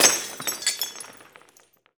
Index of /90_sSampleCDs/Roland - Rhythm Section/PRC_Guns & Glass/PRC_Glass Tuned